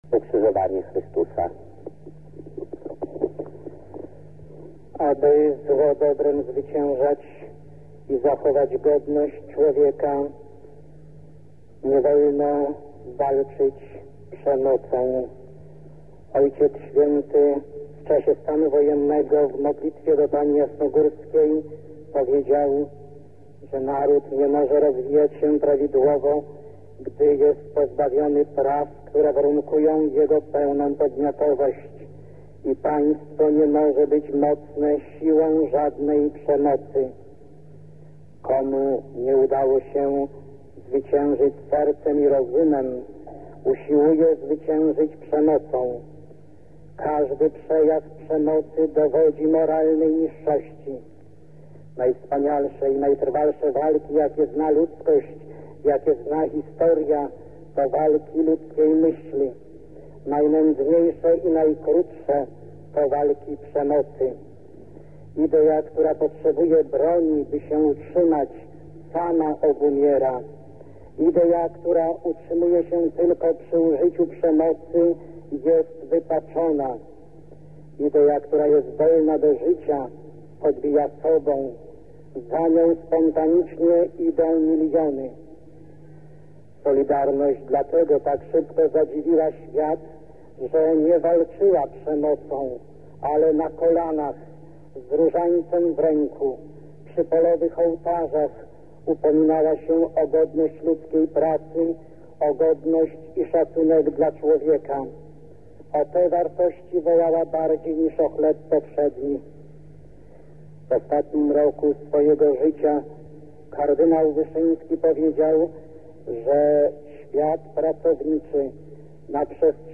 19 października 1984 o godz. 18, w bydgoskim kościele Braci Męczenników ks. Jerzy Popiełuszko odprawił nabożeństwo różańcowe.
Archiwalne nagranie z mową ks. Jerzego